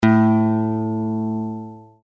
Use the audio tones below to tune your guitar to an Open D Tuning (commonly used for playing slide).
A String